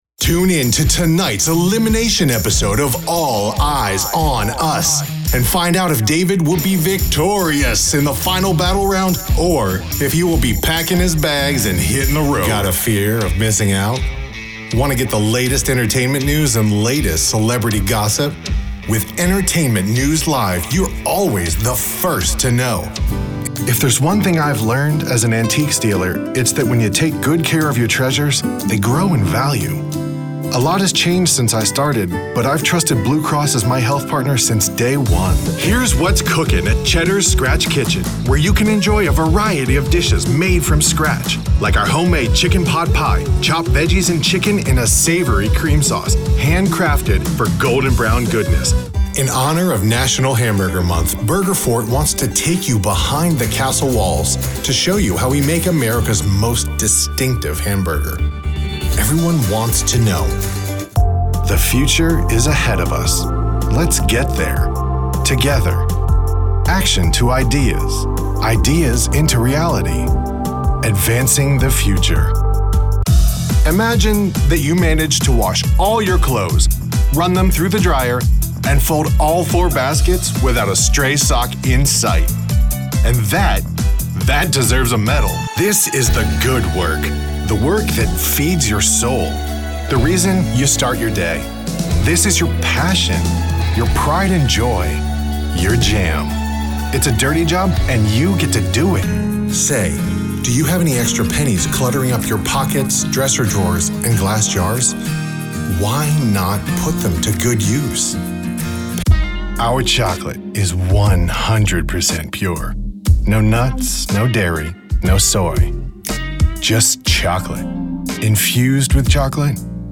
announcer, attitude, confident, cool, english-showcase, mellow, neutral, Straight Forward